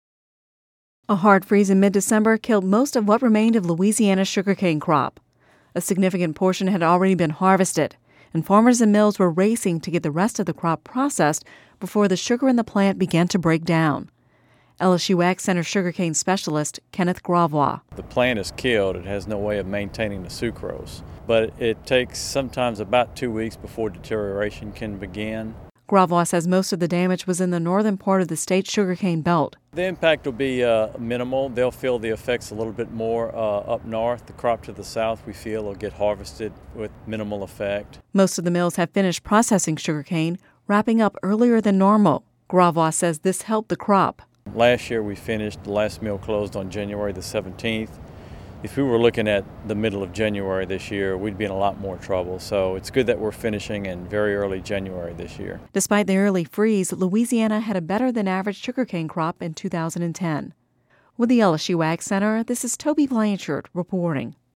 (Radio News 01/03/11) A hard freeze in mid-December killed most of what remained of Louisiana’s sugarcane crop. A significant portion had already been harvested by the time the freeze came, however, and farmers and mills were racing to the get the rest of the crop processed before the sugar in the cane plants began to break down.